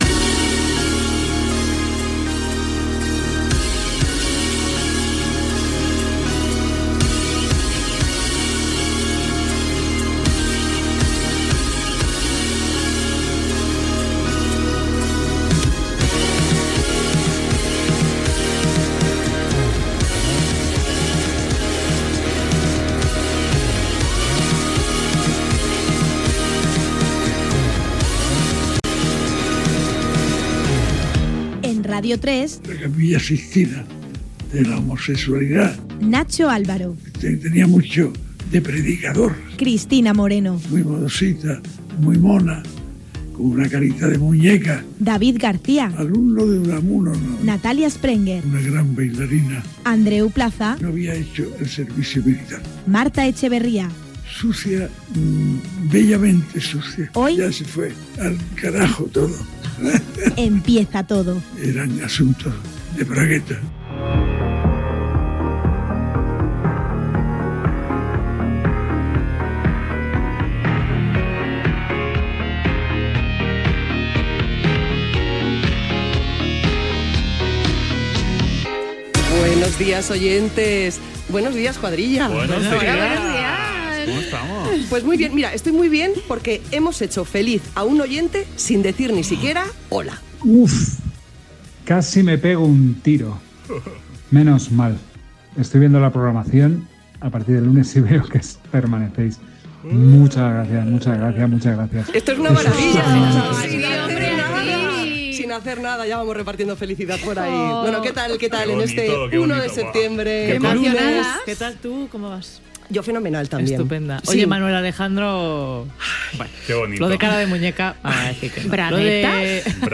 Inici de la temporada 2025-2026. Sintonia, equip, identificació del programa. Salutació als col·laboradors. Sumari i noves seccions.
Entreteniment